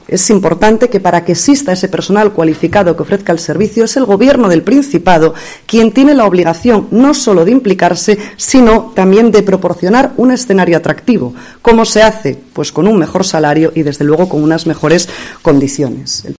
“Esta consejera solo reacciona a golpe de cacerolada, y esto no es gestionar”, ha advertido la diputada del PP este lunes en rueda de prensa desde la Junta General del Principado, donde ha asegurado que las trabajadoras del SAD son “un ejemplo más del hartazgo” de miles de asturianos ante la “gestión antisocial” del Gobierno de Adrián Barbón.